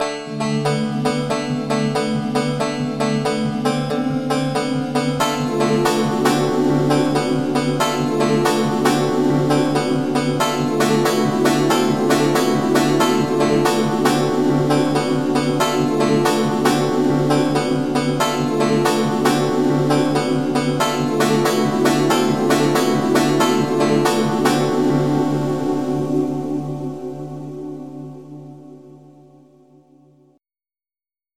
channel 6 is patch 91 pan 10, which is a Pad 4 (choir)
channel 7 is patch 88 pan 90, which is a Pad 7 (halo), and
or on channels with different, more ethereal, patches with